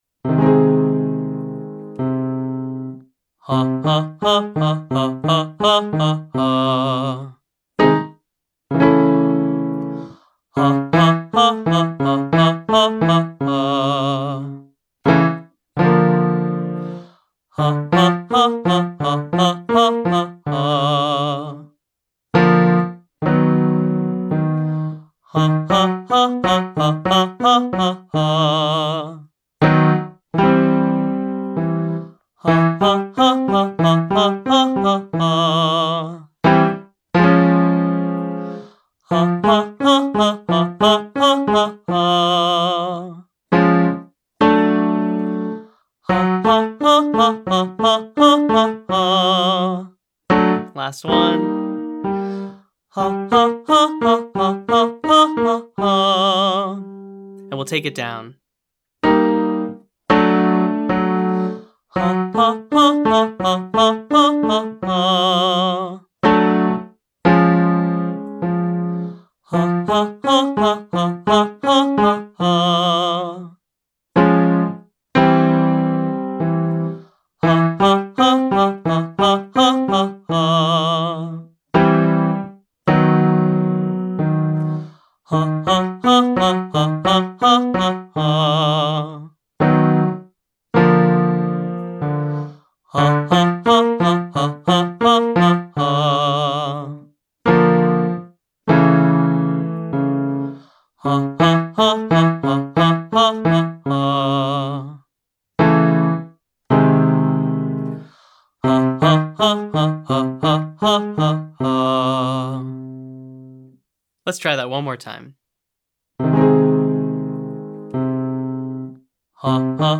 Staccato Exercises
Staccato involves singing short, detached notes, which is the opposite of smooth, connected legato singing.
2. Staccato Arpeggio Exercise (Hah):
Vocal Agility Daily Warmup for Low Voice-2B